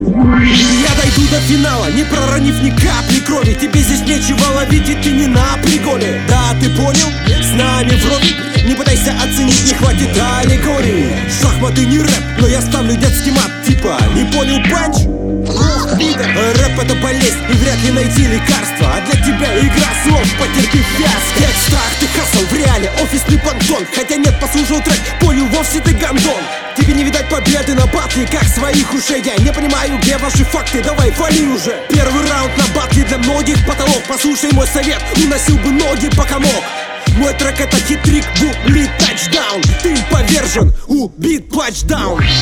В целом чутка недотянул, как мне кажется, нет у тебя уверенности на бите, флоу не от
Читка крутая, но раскрытие взято с потолка.